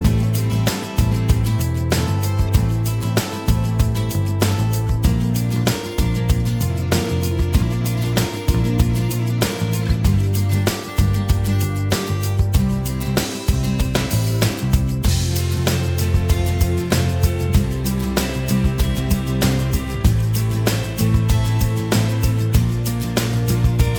Minus All Guitars Pop (1990s) 3:30 Buy £1.50